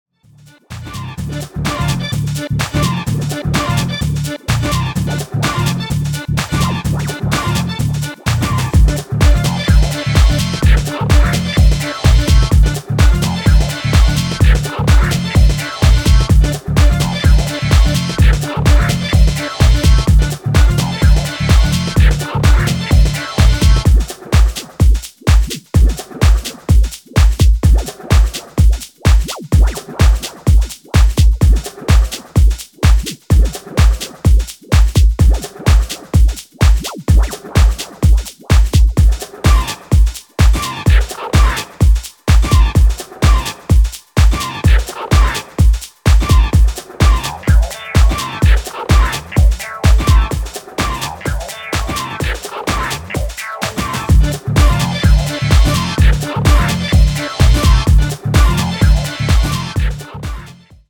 どちらもシンプルでフロア訴求力抜群な仕上がりが推せます！